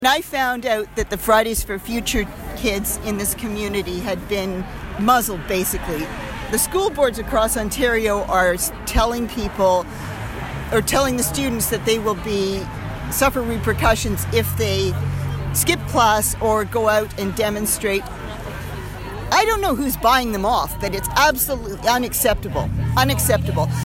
That was the message delivered on Friday in front of MP Neil Ellis’ office at a climate rally.